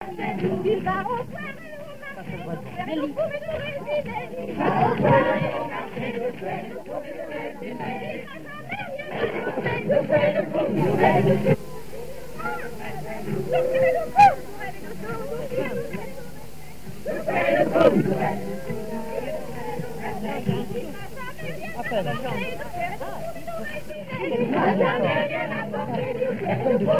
Île-d'Yeu (L')
danse : ronde : demi-rond
chansons à danser ronds et demi-ronds
Pièce musicale inédite